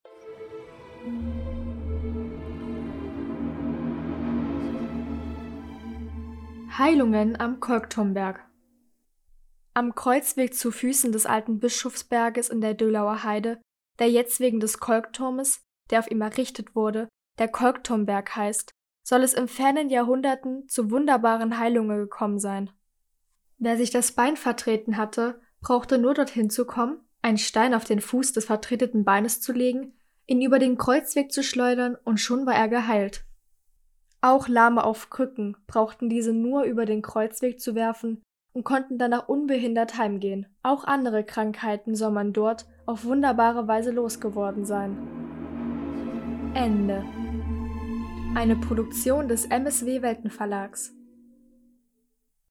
Sagen aus der Umgebung von Halle (Saale), gelesen von der